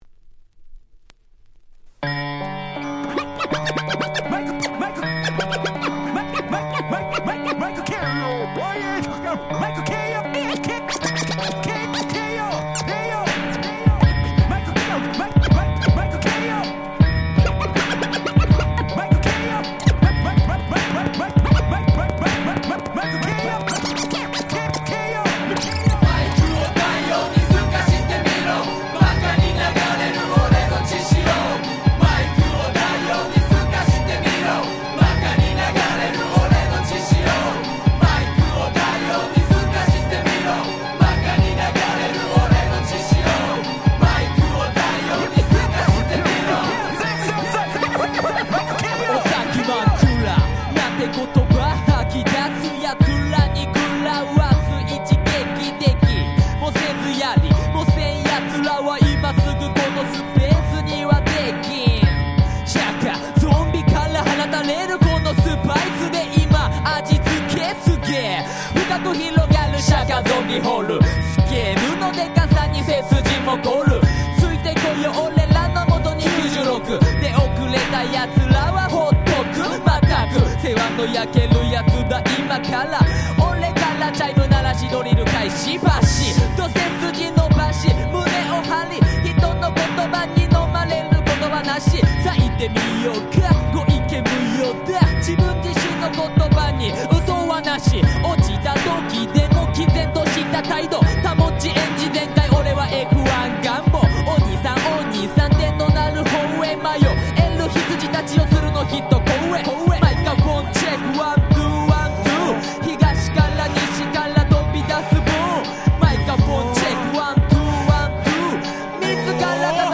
96' Japanese Hip Hop Classics !!
さんピン期の問答無用の日本語ラップクラシック。
J-Rap 90's